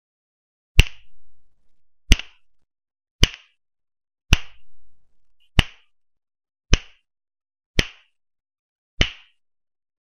Шум ударов кожаным ремнем